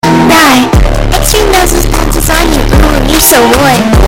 OwO Earrape
OwO-EarKiller.mp3